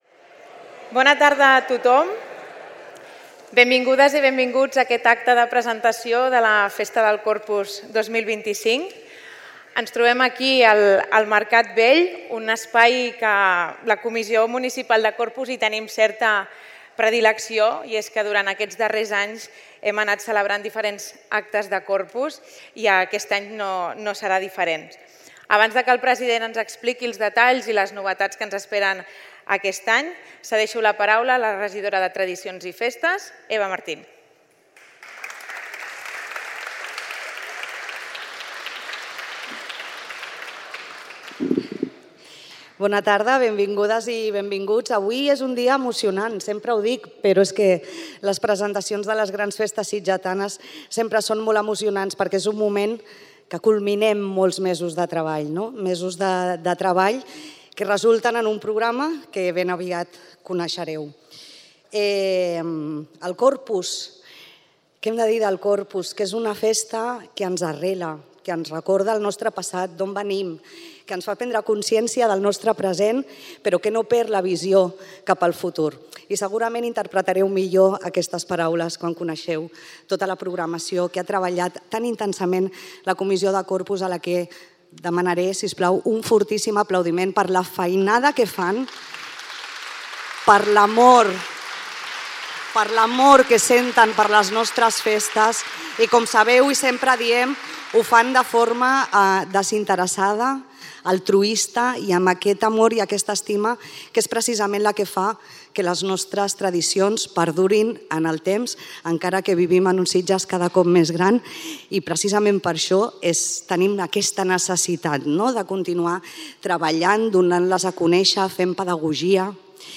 El Mercat Vell acollí, ahir, l'acte de presentació del Corpus d'enguany. El Corpus d'aquest 2025 que ve marcat, sobretot, per dues importants efemèrides: el 50è aniversari dels gegants nous i els 75 anys de la catifa del primer tram del carrer Jesús.
Escolteu l’acte sencer de presentació del Corpus 2025